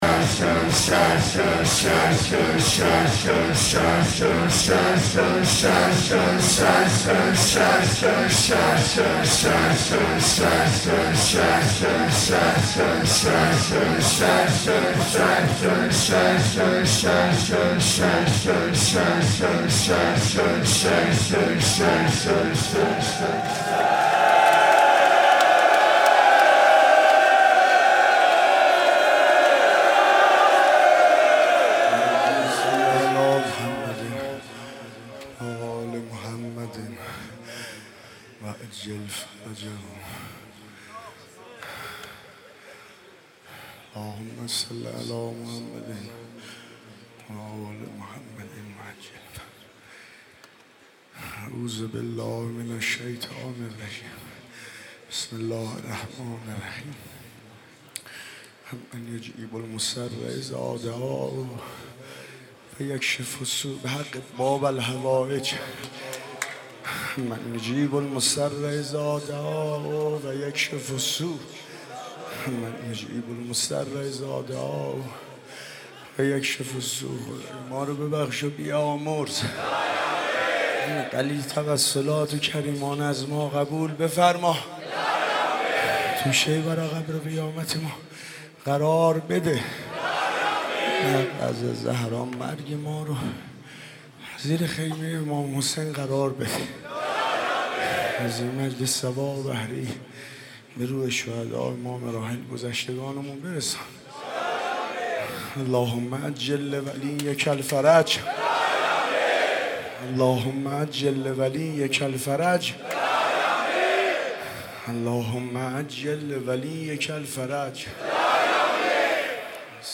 شب هفتم محرم95/هیئت الرضا
سینه زنی